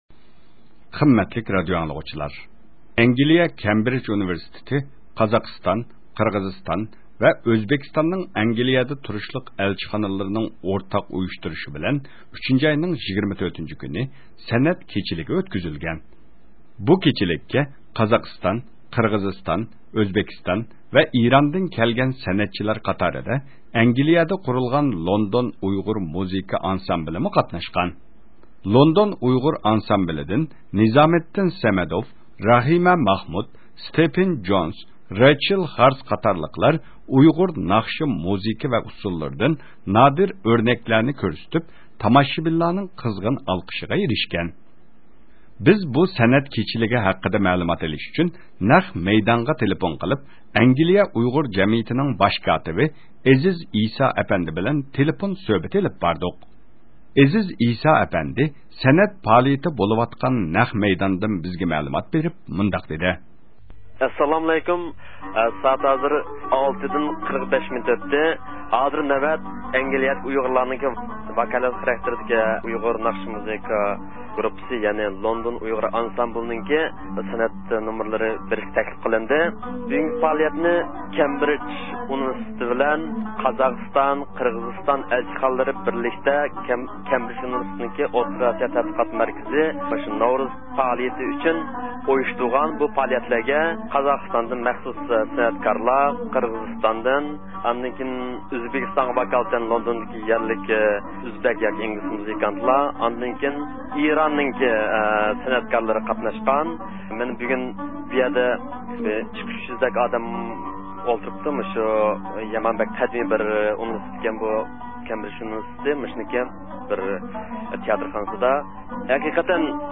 بىز بۇ سەنئەت كېچىلىكى ھەققىدە مەلۇمات ئېلىش ئۈچۈن نەق مەيدانغا تېلېفون قىلىپ